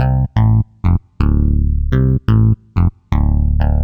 cch_bass_sfur_125_Dm.wav